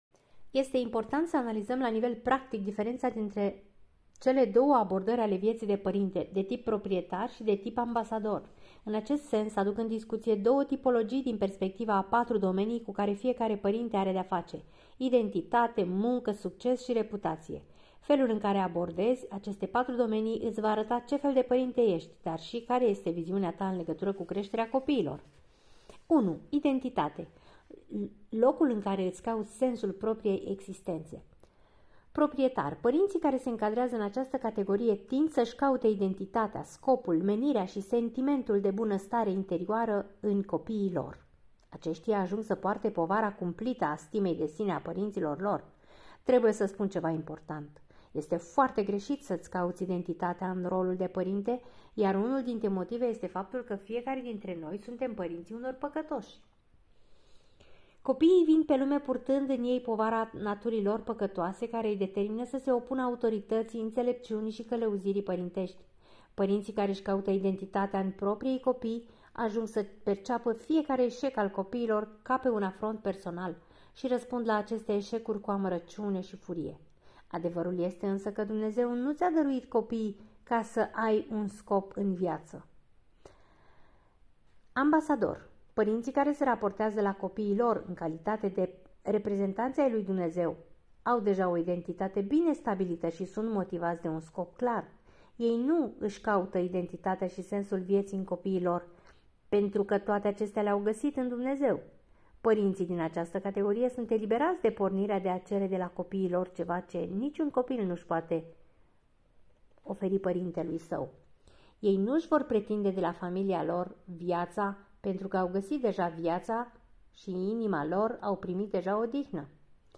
Este Introducerea al cărții "Pentru părinți - 14 principii care îți pot schimba radical familia" de la Paul David Tripp.